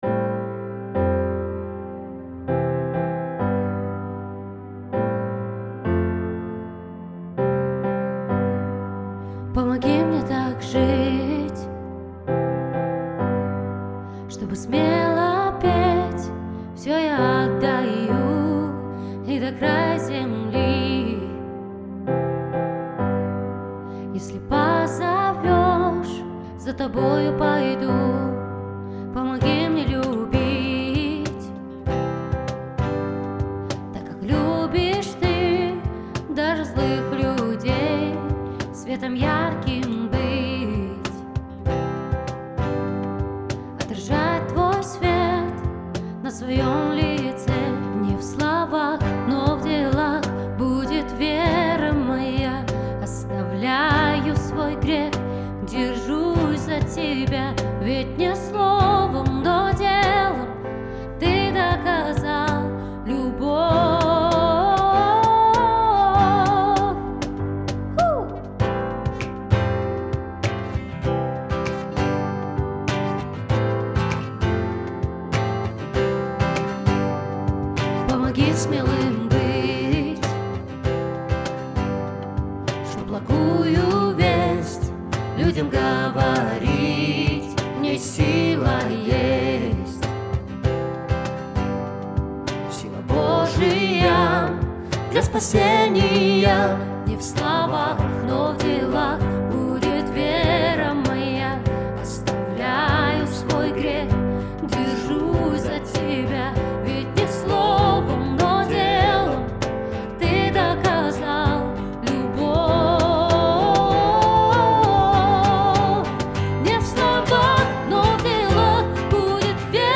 песня
505 просмотров 450 прослушиваний 39 скачиваний BPM: 98